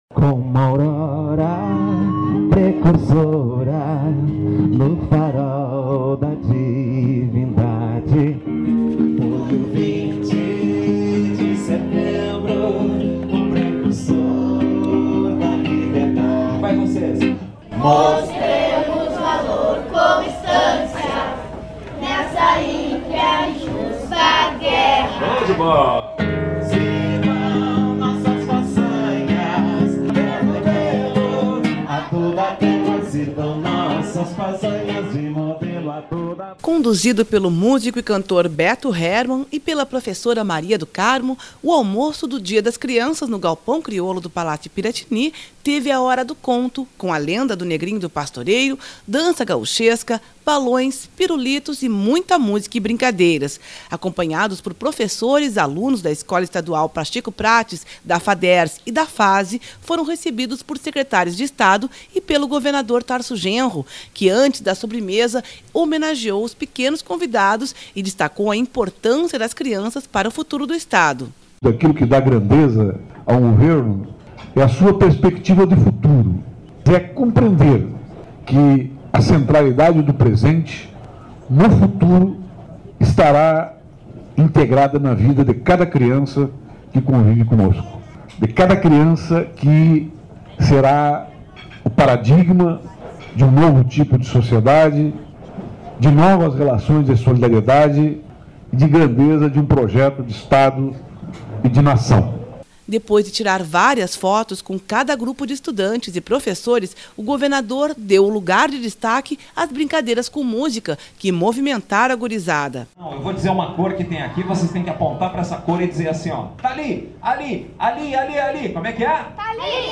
Música e brincadeiras no almoço do Dia das Crianças no Piratini